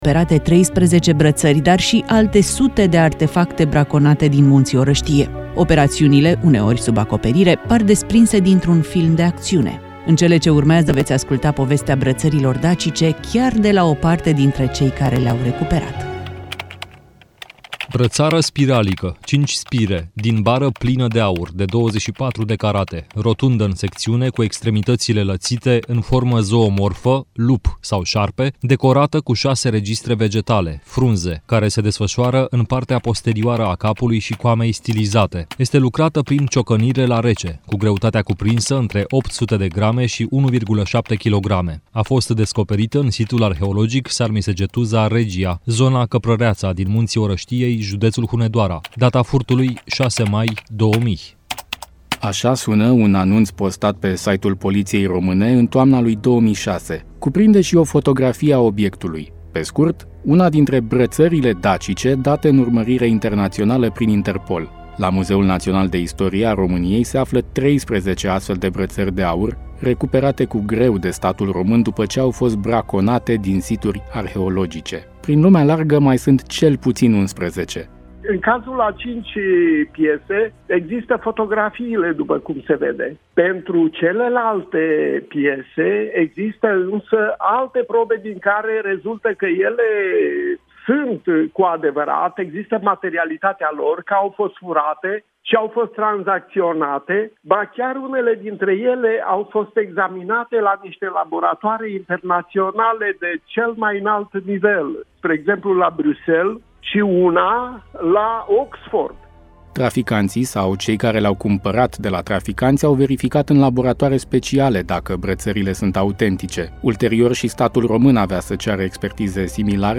Într-o intervenție telefonică la emisiunea „România în Direct” la Europa FM
reportaj-LEFM-pt-RID-.mp3